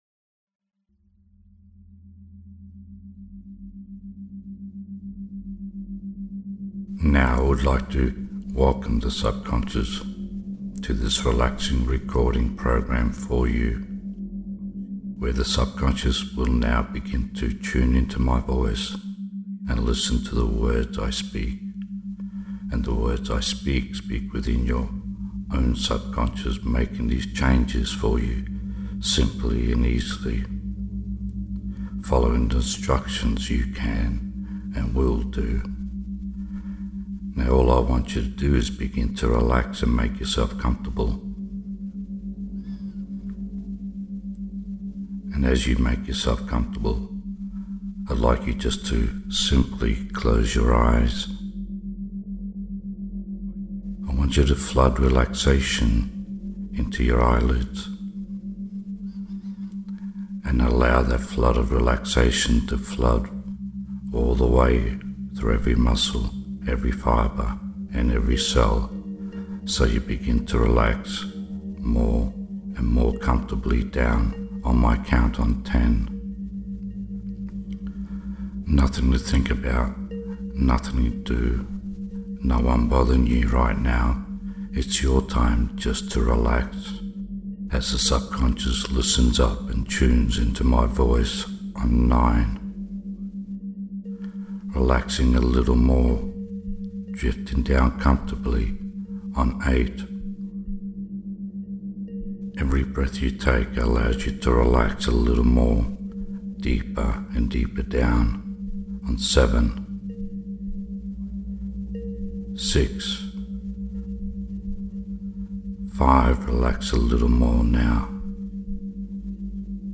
Hypnosis-For-Alzheimers.mp3